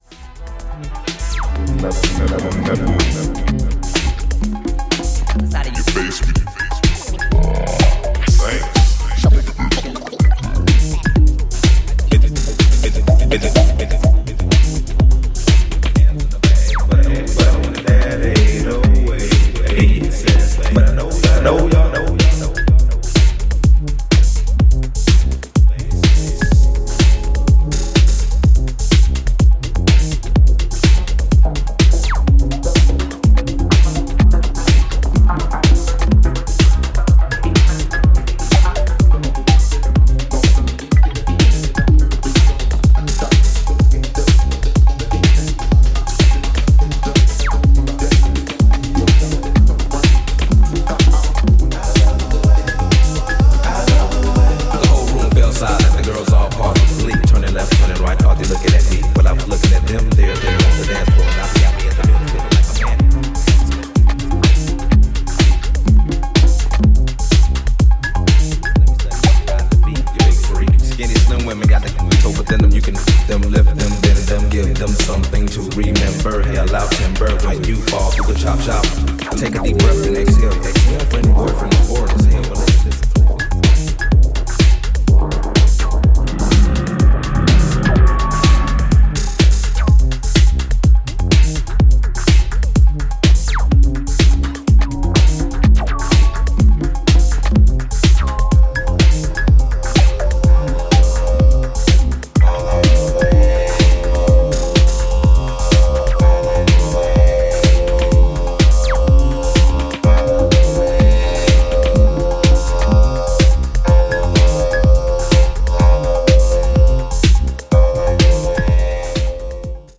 4 minimal, dub and house beautiful tracks